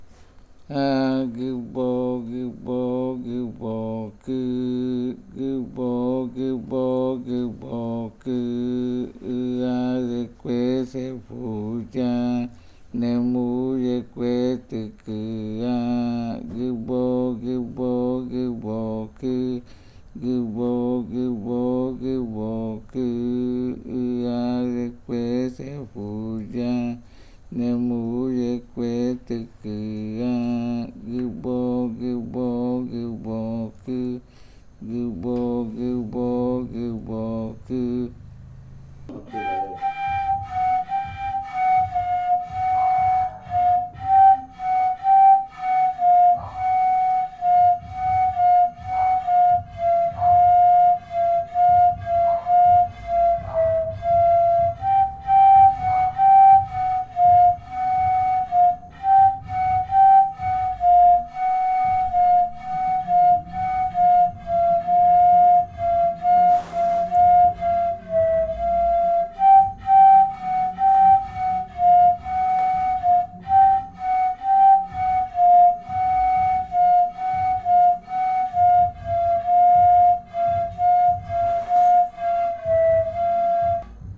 Leticia, Amazonas, (Colombia)
Canto Gɨbokɨ (lengua murui) e interpretación del canto en pares de reribakui.
flauta hembra
flauta macho
Gɨbokɨ chant (Murui language) and performance of the chant in reribakui flutes.
smaller, female flute
larger, male flute